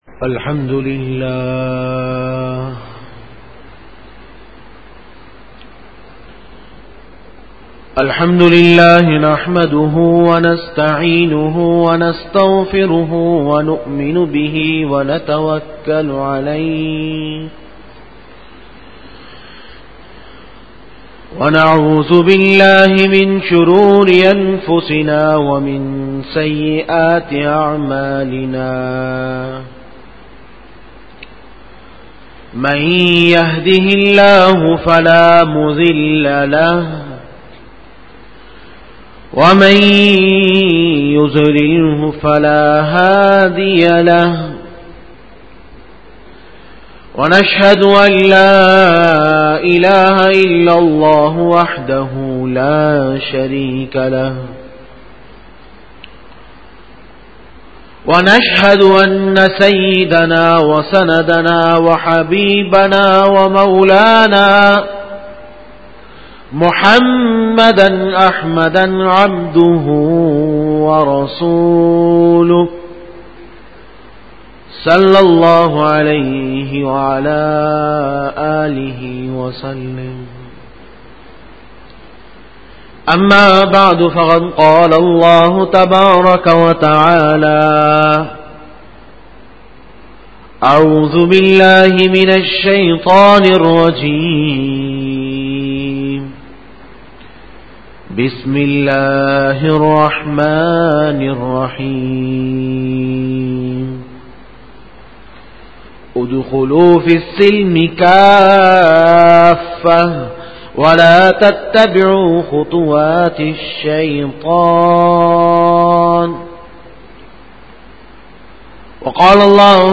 10 Bayan e juma tul mubarak 08-March-2013